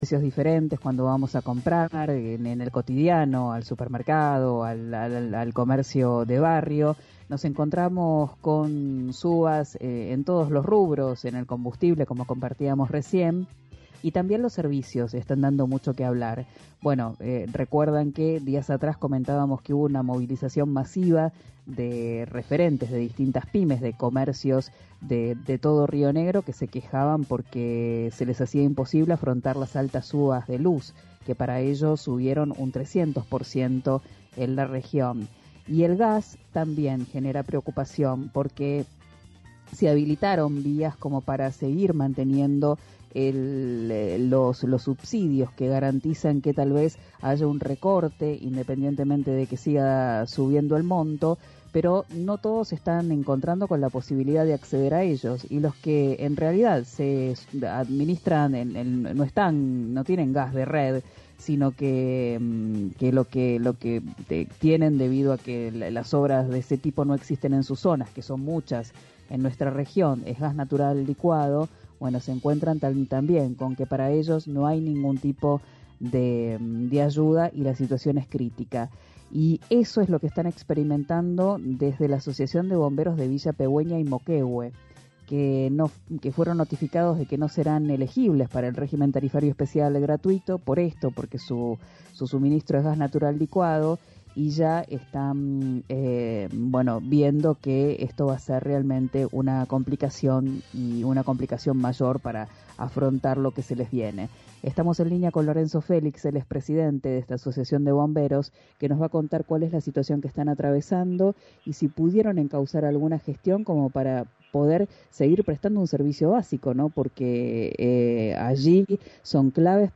en RÍO NEGRO RADIO: